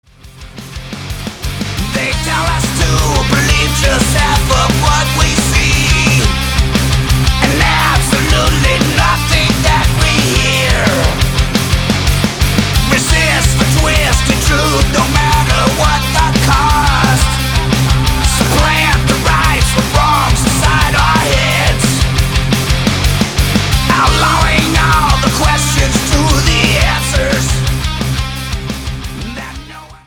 Studio: Lattitude South Studios, Leiper's Fork, Tennessee
Genre: Thrash Metal, Heavy Metal